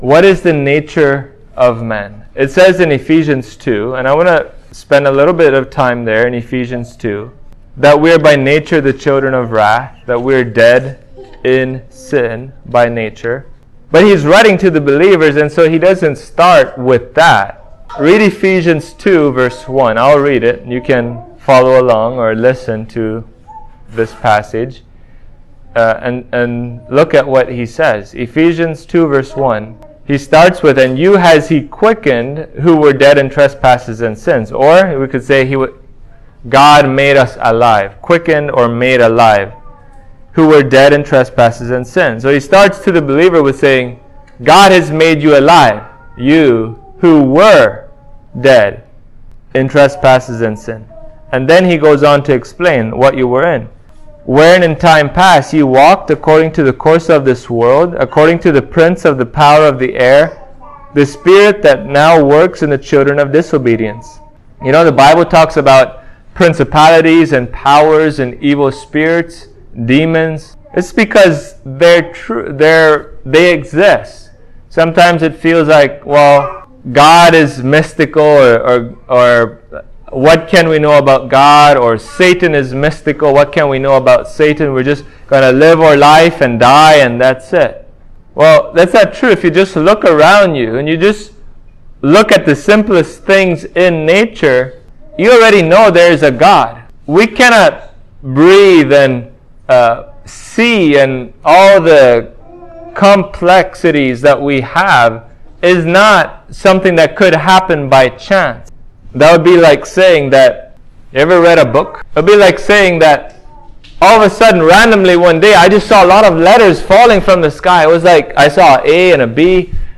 Ephesians 2:1-6 Service Type: Sunday Morning What is the nature of man?